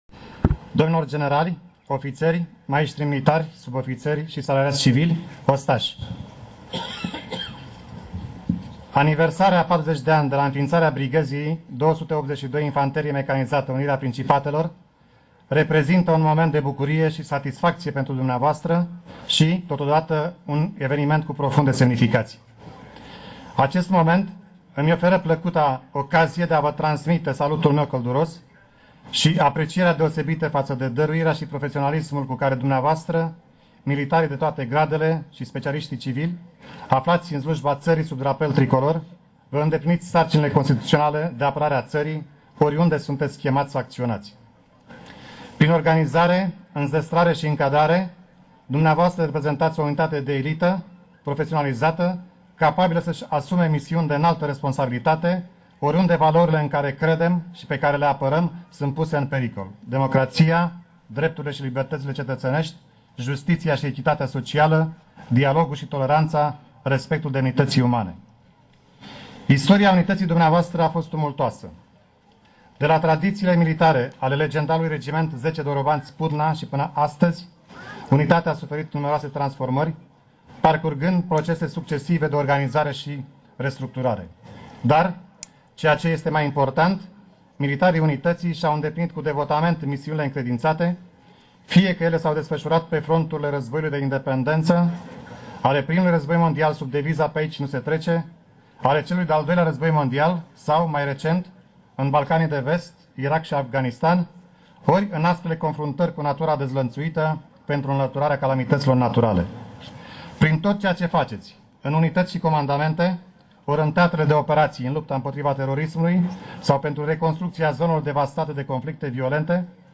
Citirea mesajului președintelui României de către gl.lt.dr. Ion Oprișor